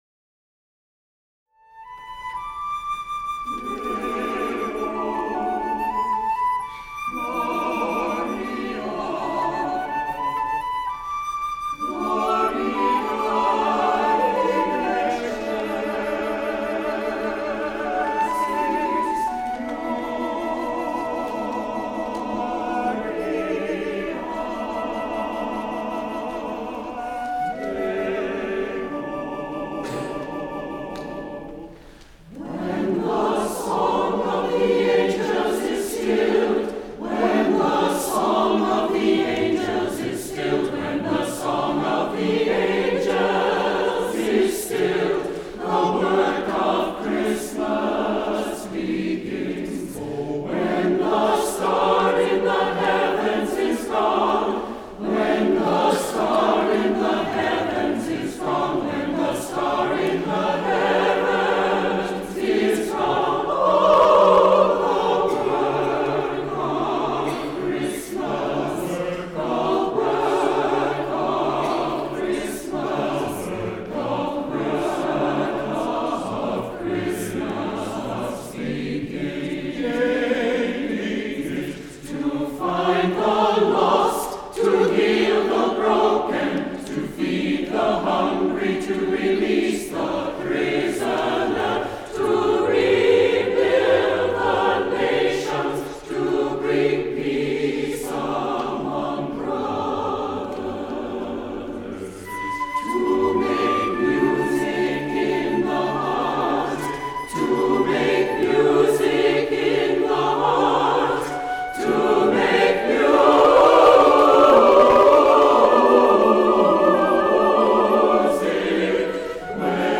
SATB, flute